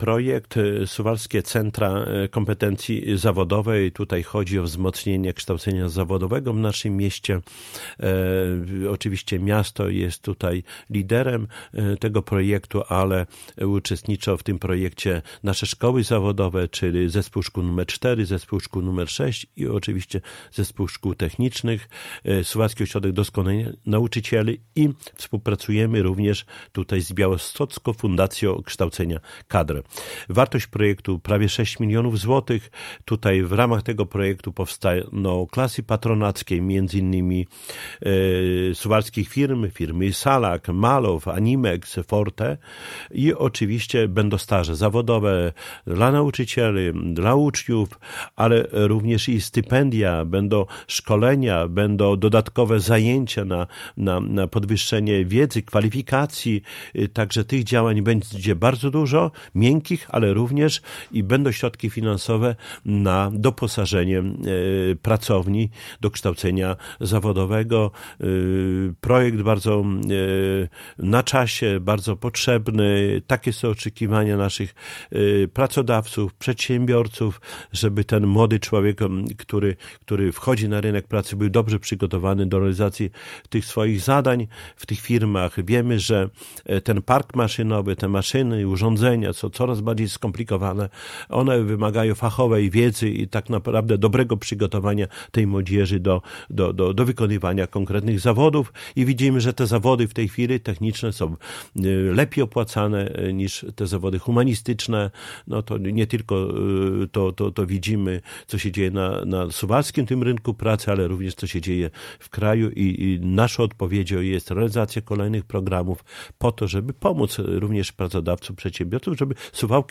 Cieszę się, że nasze projekty są dobrze oceniane i finansowane, za co też bardzo dziękuję – mówił w piątek (27.10) w Radiu 5 Czesław Renkiewicz, Prezydent Suwałk.